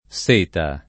seta
seta [ S% ta ]